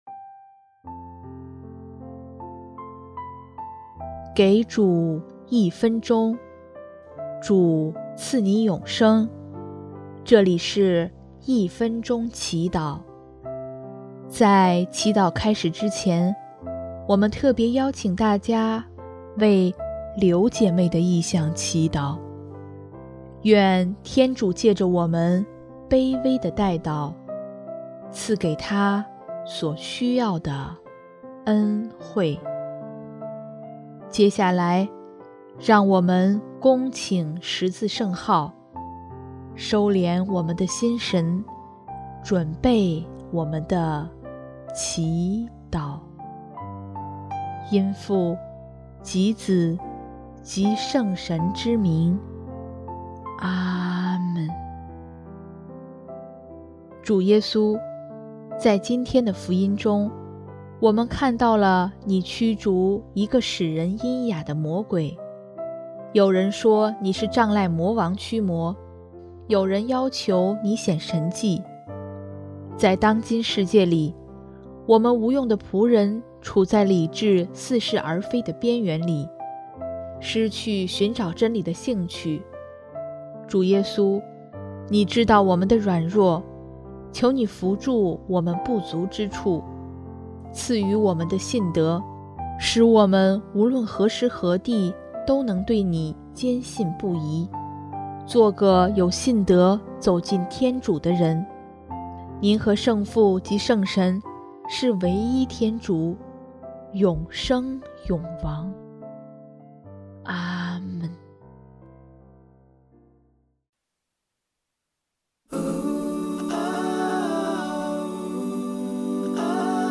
音乐： 第二届华语圣歌大赛参赛歌曲《相信》